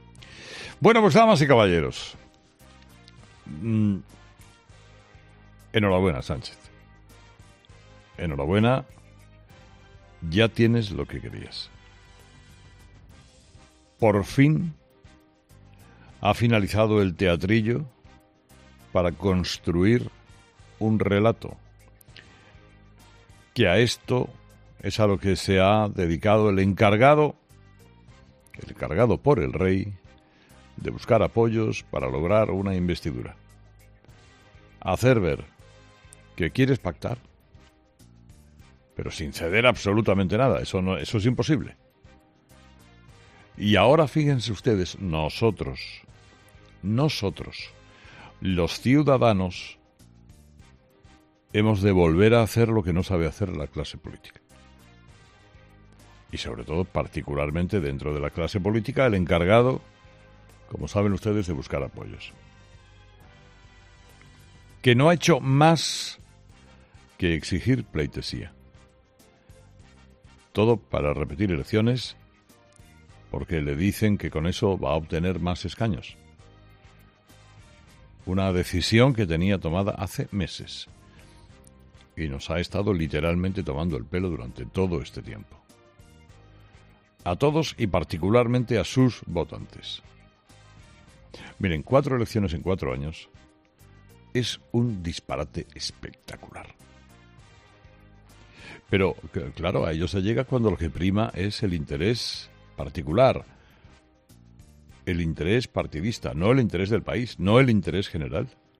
Carlos Herrera ha comenzado la mañana de este miércoles felicitando de manera irónica al presidente del Gobierno en funciones, Pedro Sánchez, por el éxito obtenido en su estrategia de consecución de una repetición electoral.